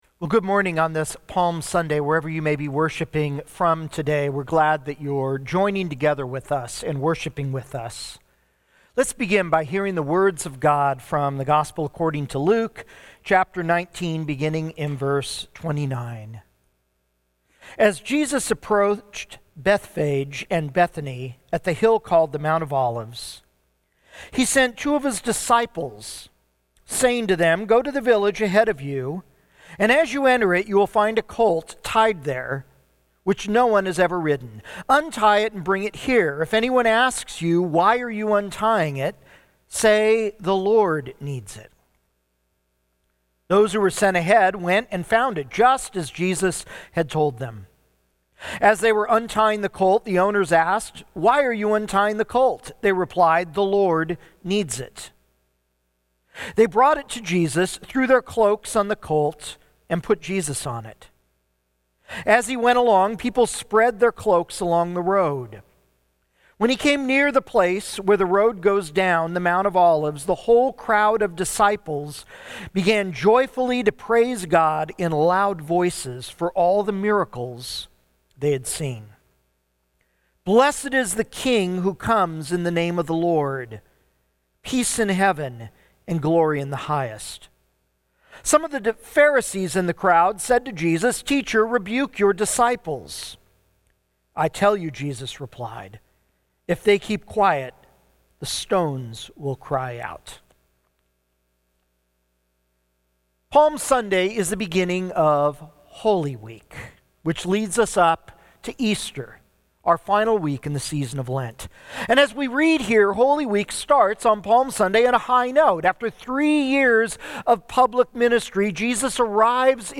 March 28, 2021 – Palm Sunday (Sermon Only) – Glenkirk Church